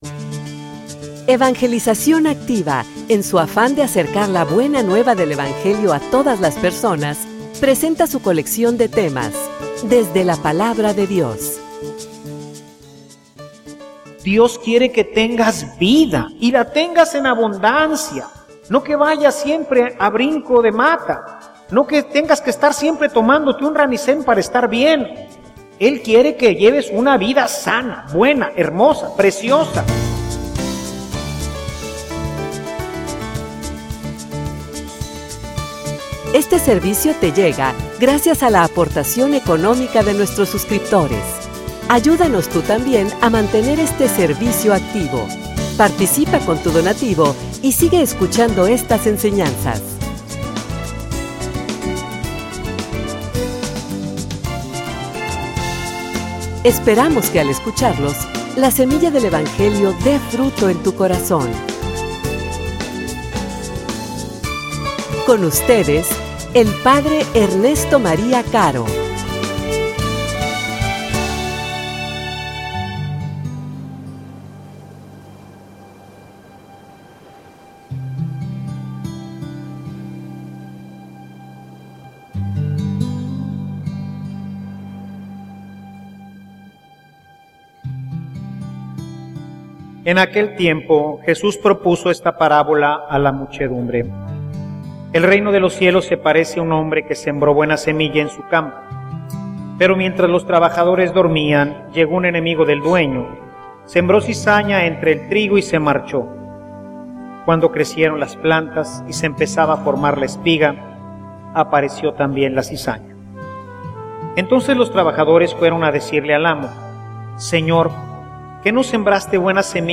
homilia_Dios_quiere_que_seas_feliz.mp3